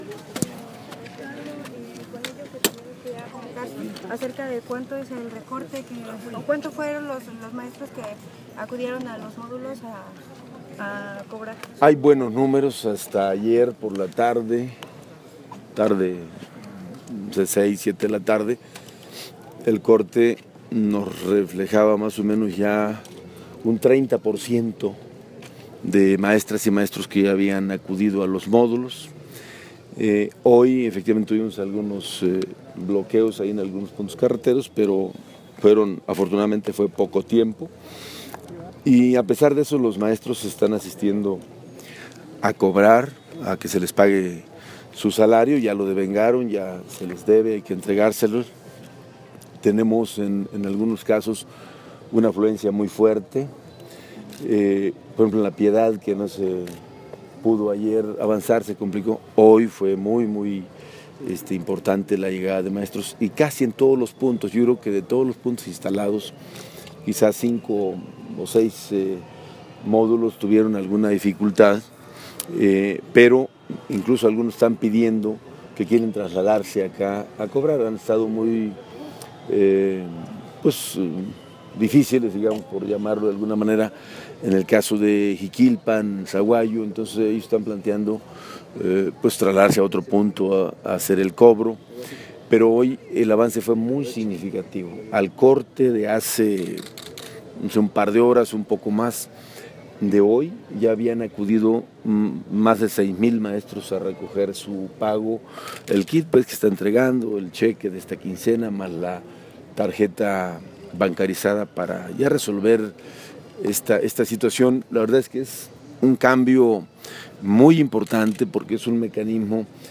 En entrevista, luego de inaugurar el Campeonato Nacional de Natación Curso Largo Michoacán 2016, el Gobernador de Michoacán, cambio su discurso, de conciliador y hasta de convocar a la CNTE al diálogo político, a uno que abre la puerta a la especulación electoral rumbo a la carrera presidencial y canto a tiro abierto lo siguiente: